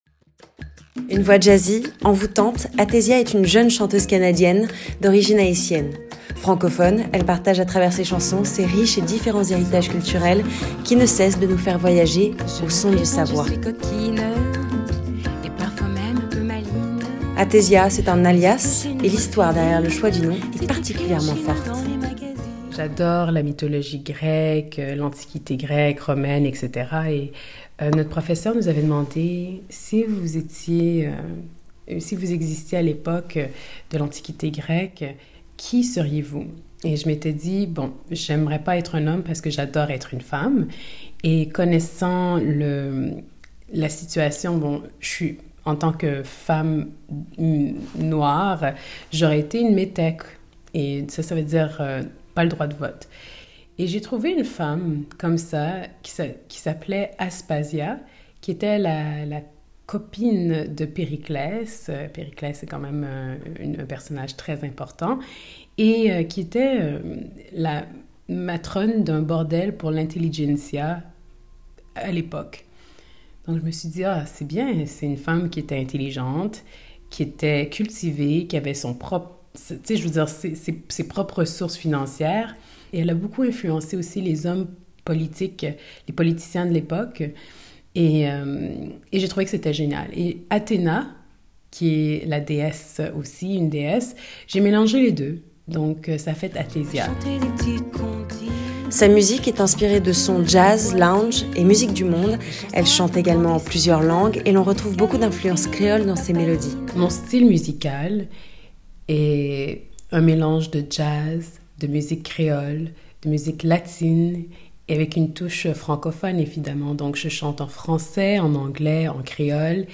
Avec sa voix jazzy et son style lounge, elle chante en plusieurs langues et nous transporte avec elle sur ses terres d'origines. Cette interview date de mai 2018